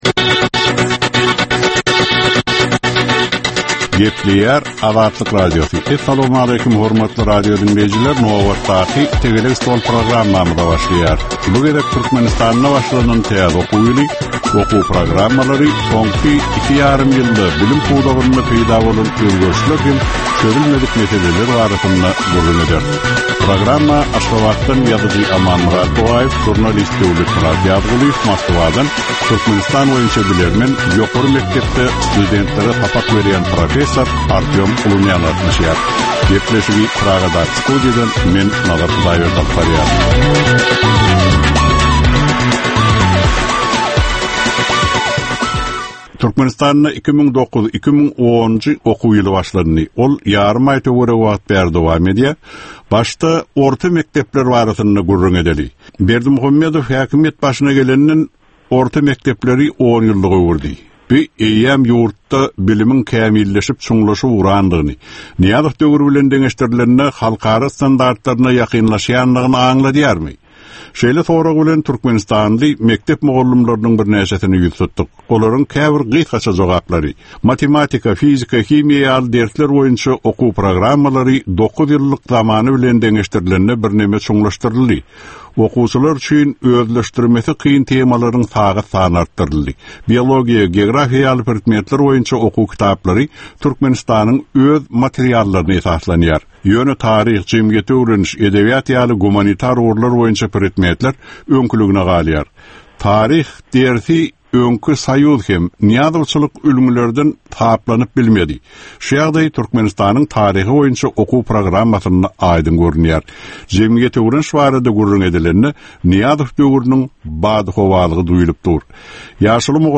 Jemgyýetçilik durmusynda bolan ýa-da bolup duran sonky möhum wakalara ýa-da problemalara bagyslanylyp taýyarlanylýan ýörite Tegelek stol diskussiýasy. 30 minutlyk bu gepleshikde syýasatçylar, analitikler we synçylar anyk meseleler boýunça öz garaýyslaryny we tekliplerini orta atýarlar.